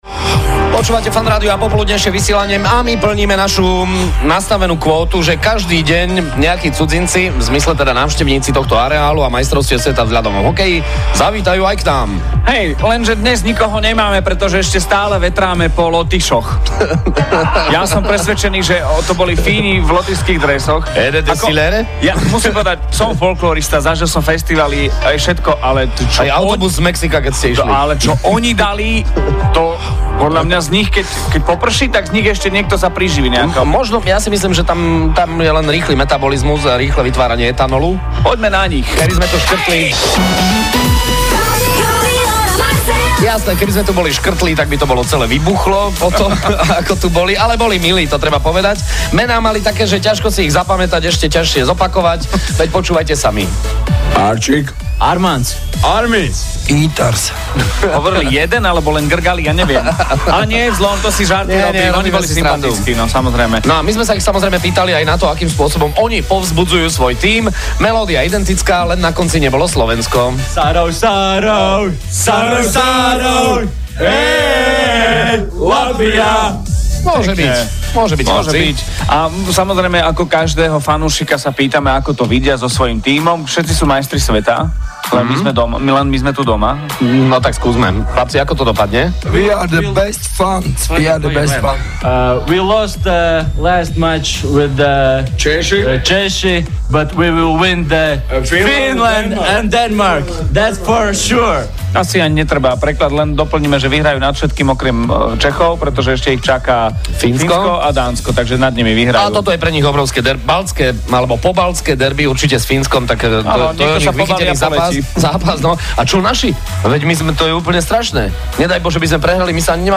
vysielajú zo štúdia priamo vo Fan Village, mohli u seba naživo privítať lotyšských hokejových fanúšikov, ktorí rozhodne stáli za to :)